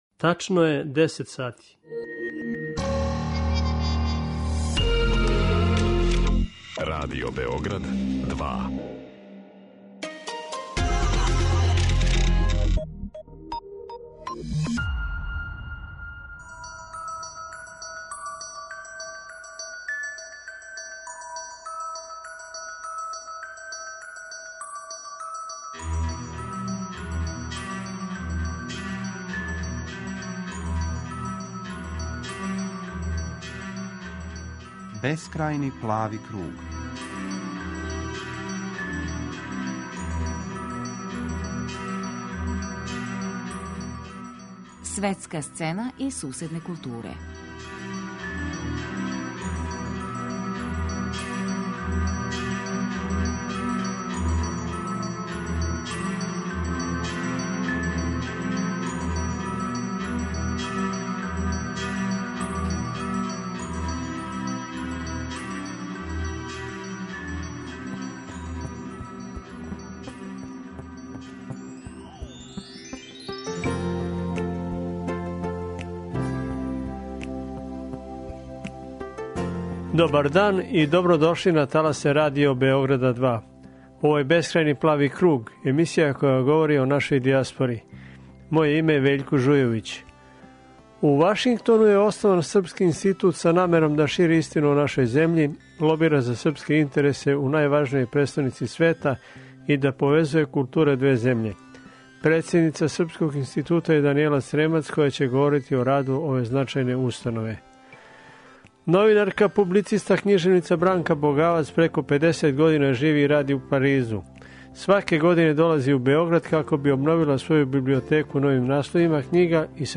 Снимили смо разговор о њеном животу и раду у Француској.